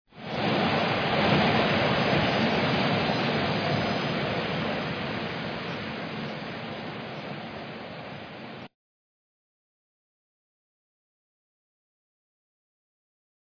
Waterfall Sound Effects
Panning waterfall with beautiful wind chimes sound effects. 10 seconds.
32kbps-CG-Chimes-and-Water.mp3